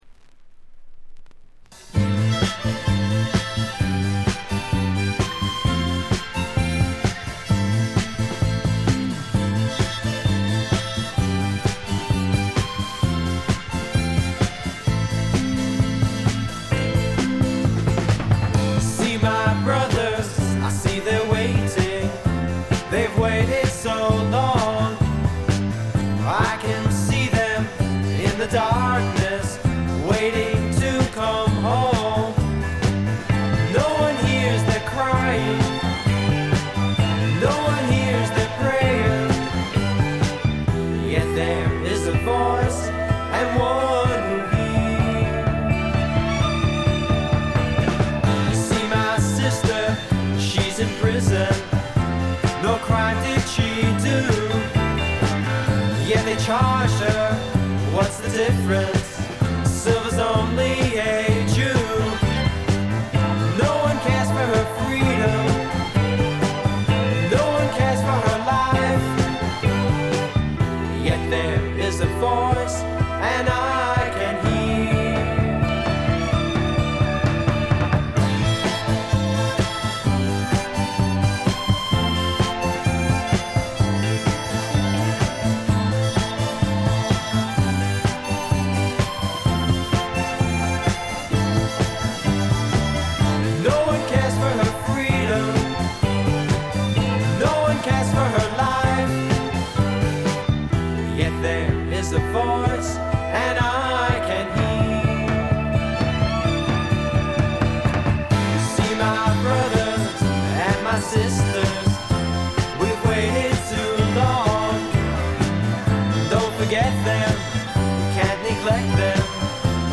ほとんどノイズ感無し。
演奏、歌ともに実にしっかりとしていてメジャー級の85点作品。
試聴曲は現品からの取り込み音源です。